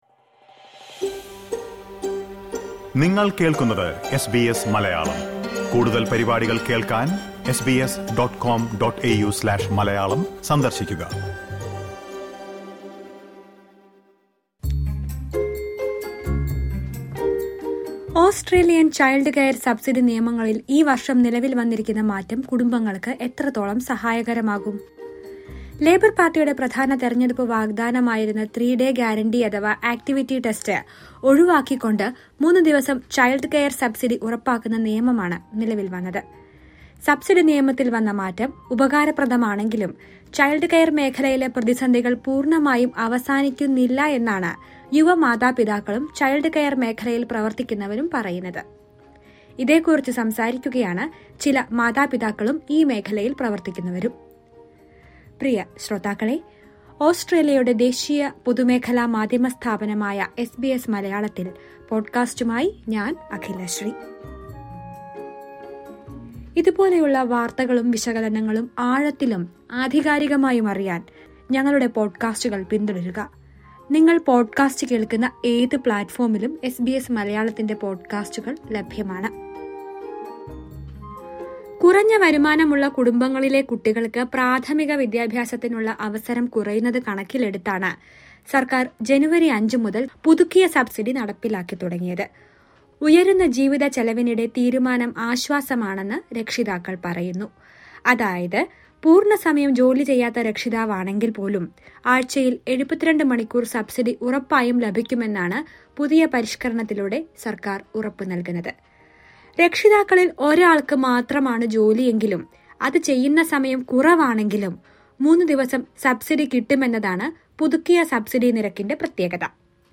ഓസ്ട്രേലിയൻ ചൈൽഡ് കെയർ സബ്സിഡി നിയമങ്ങളിൽ ഈ വർഷം നിലവിൽ വന്നിരിക്കുന്ന മാറ്റം കുടുംബങ്ങൾക്ക് എത്രത്തോളം സഹായകരമാകും? വിഷയത്തെ കുറിച്ച് സംസാരിക്കുകയാണ് ചില രക്ഷിതാക്കൾ..